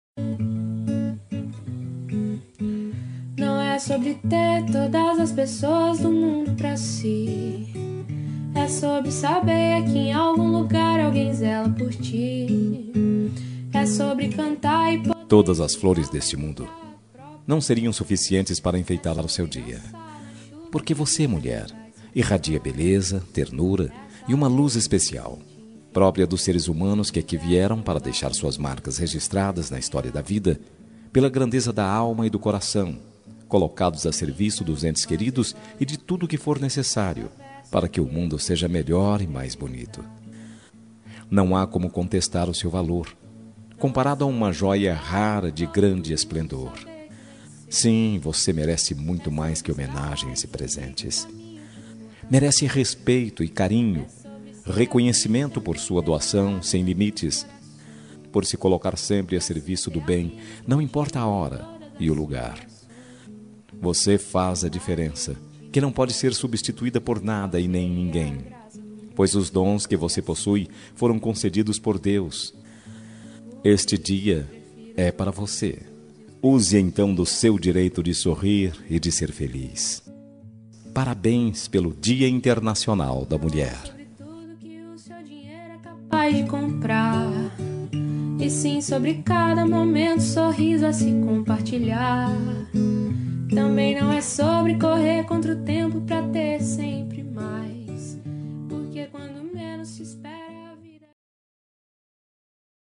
Dia das Mulheres Para Amiga – Voz Masculina – Cód: 5356 – Linda
5356-dm-amiga-masc.m4a